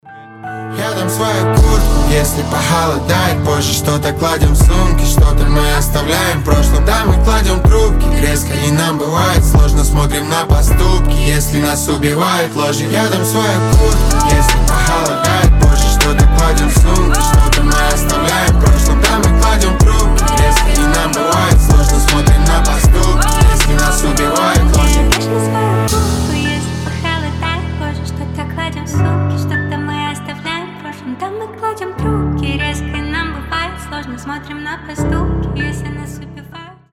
• Качество: 320, Stereo
мелодичные
дуэт